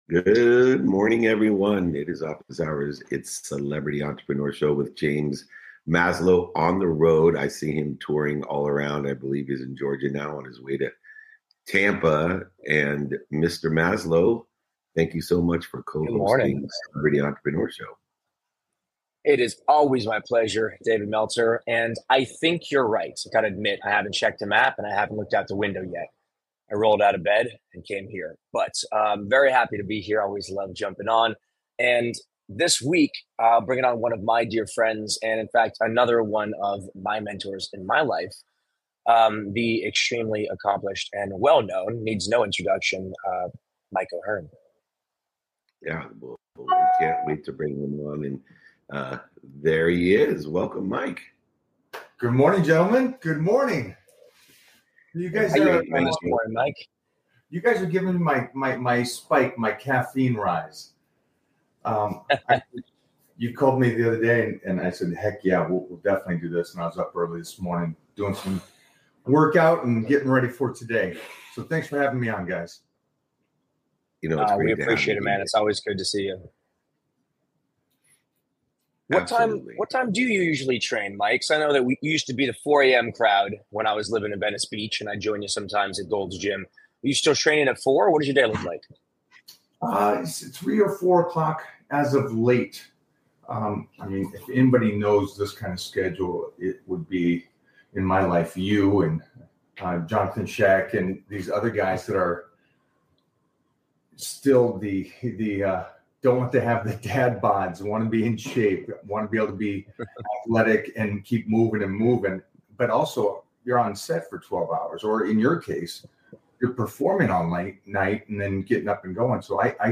In today’s episode, I’m joined by James Maslow and Mike O’Hearn for a grounded conversation about preparation, consistency, and long-term performance.